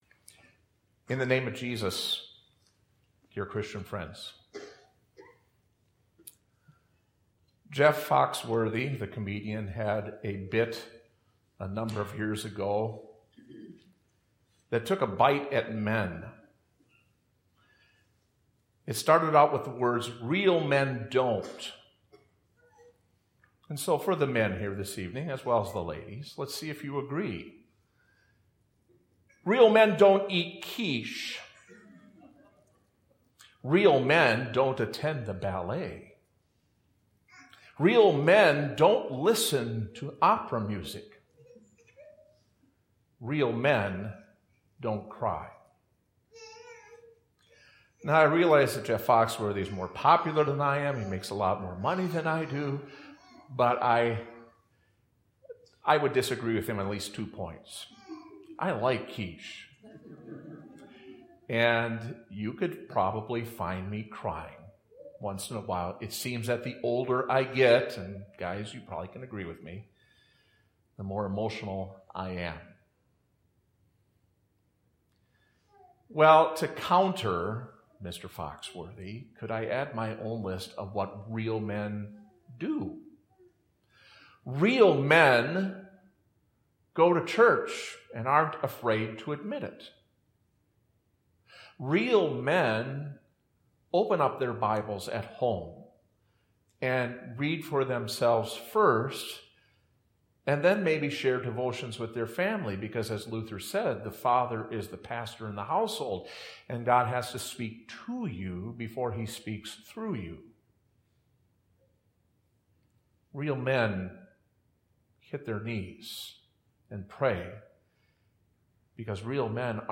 Services (the most recent service is in the first box)